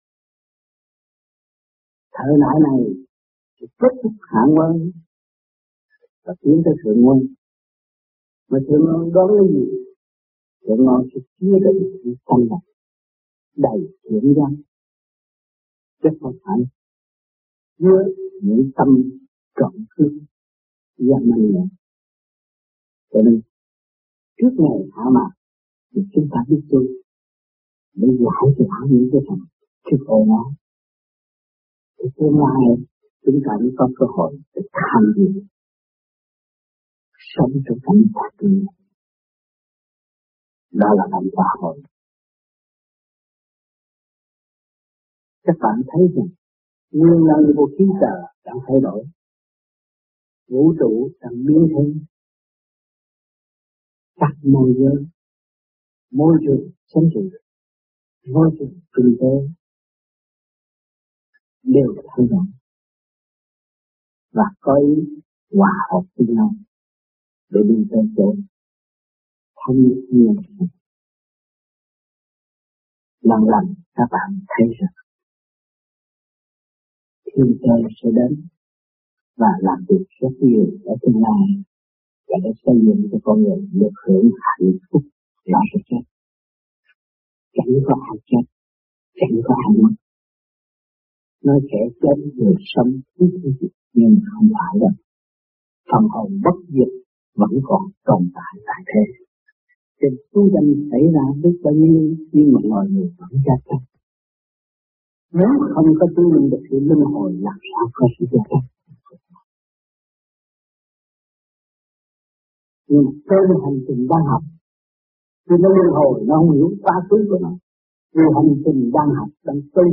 Lectures (VN)